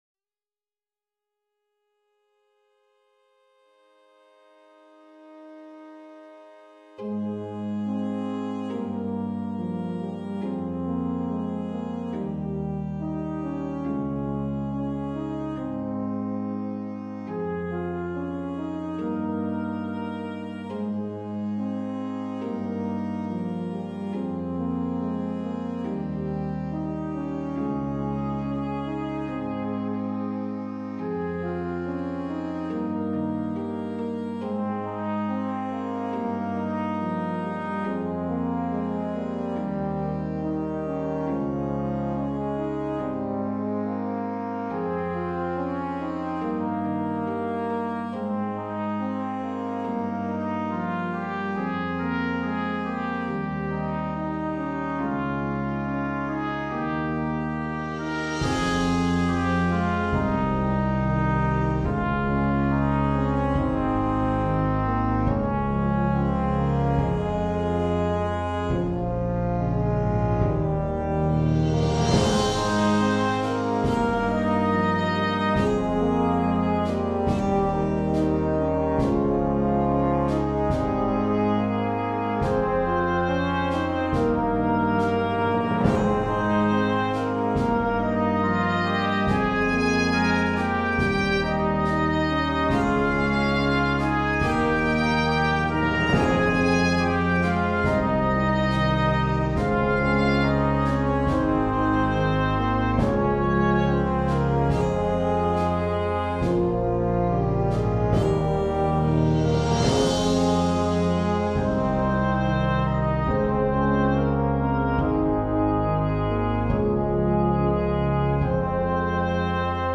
All songs were done completely by ear.